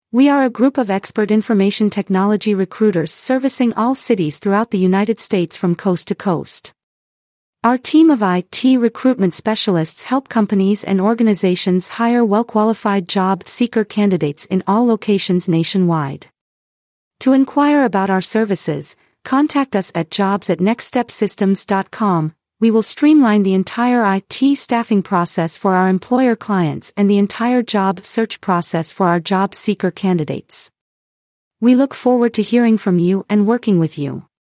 Please take a moment to listen to an audio file about our IT staffing company’s coverage area of cities generated by Artificial Intelligence (AI).